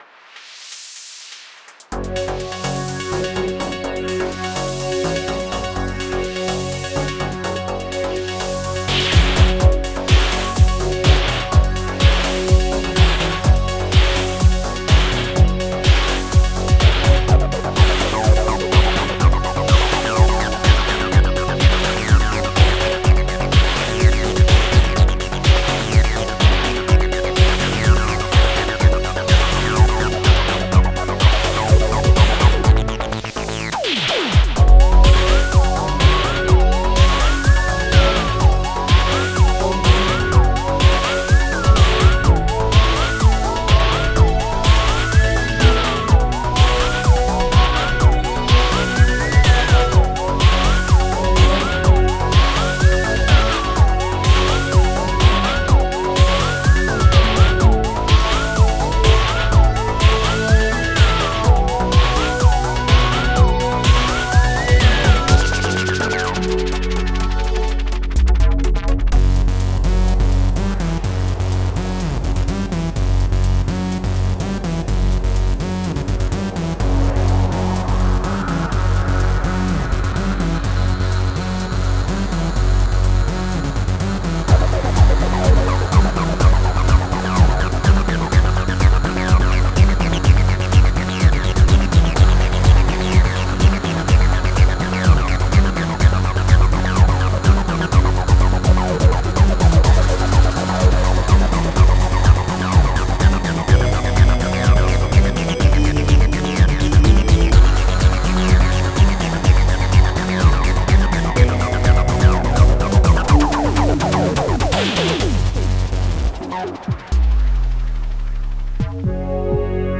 Style: Synth Dance
This track uses Synth1 as its sole sound generator.